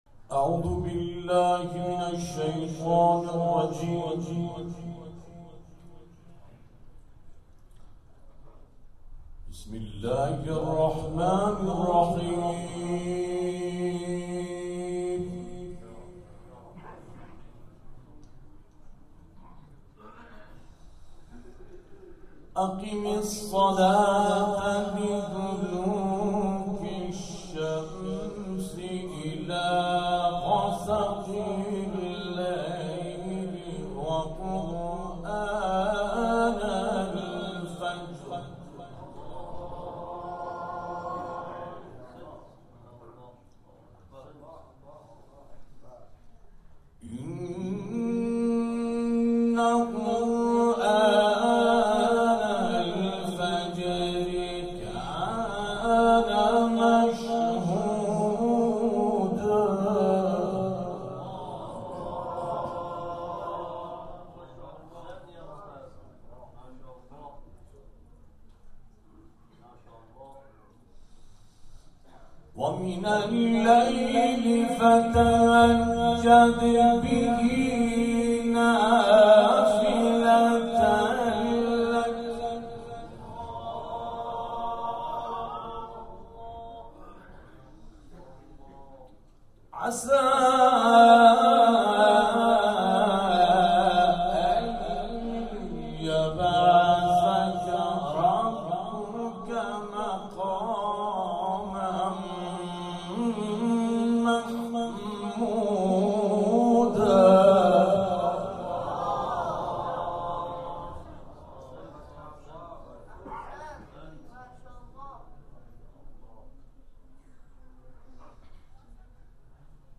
محفل قرآنی شهدای نقوسان + تلاوت قاریان بین‌المللی